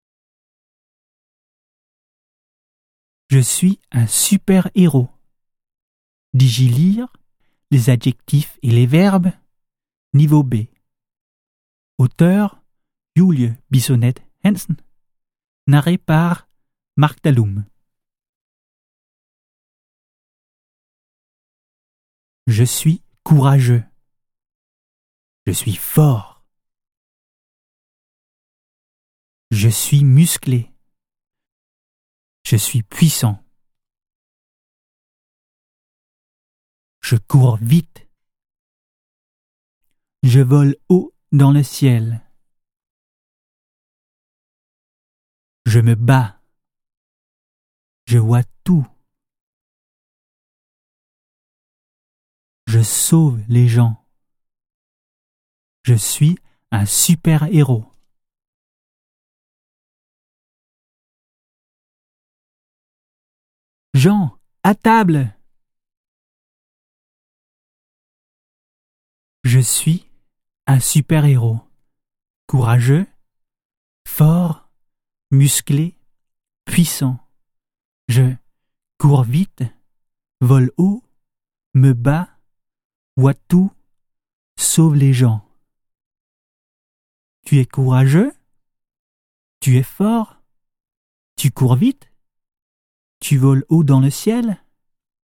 Scan QR-koden på side 2 og side 14-15 med kameraet i din smartphone eller tablet og hør oplæsning af de tilhørende tekster af en indfødt franskmand.